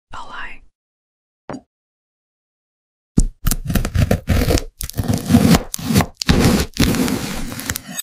Owl Eye cutting ASMR video sound effects free download